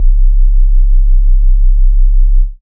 Sub Wobble C2.wav